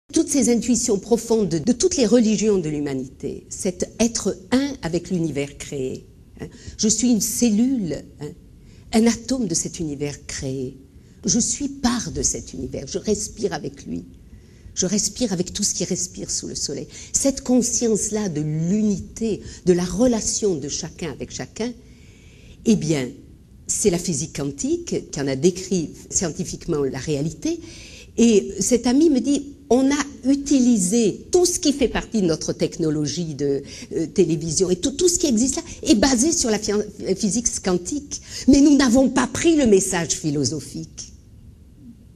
Conférencière : Christiane Singer